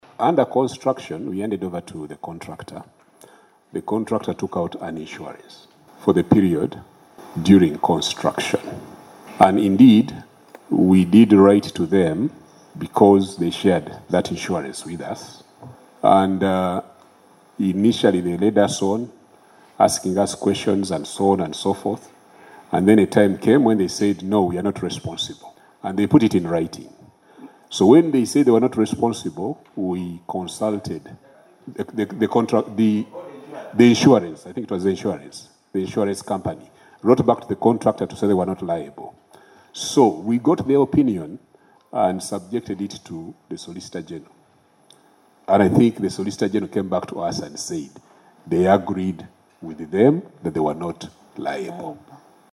Appearing before the Public Accounts Committee - Central Government (PAC - Central) on Wednesday, 21 May 2025 at Parliament House, the Ministry of Foreign Affairs Permanent Secretary, Vincent Bagiire, revealed that although Uganda approached both the Kenyan government and the contractor’s insurance provider for compensation, neither has taken responsibility.